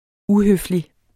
Udtale [ ˈuhøfli ]